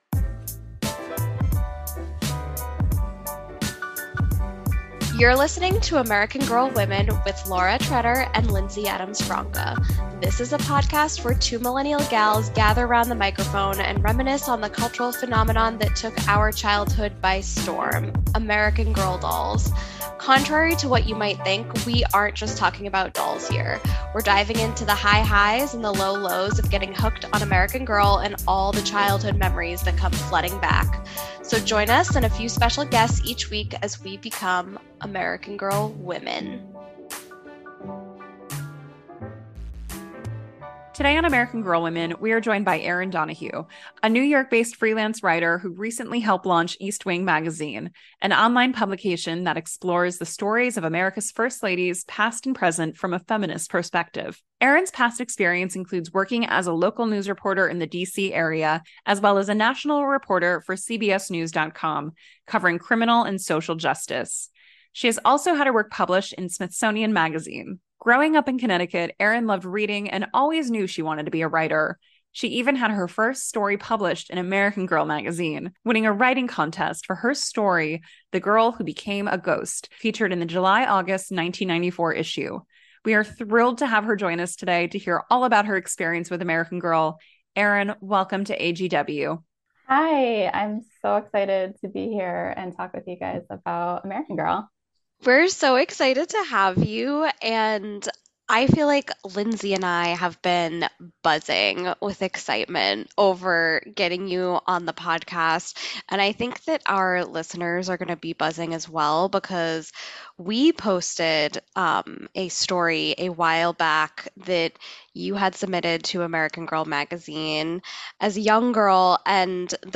We also hear a special reading of the story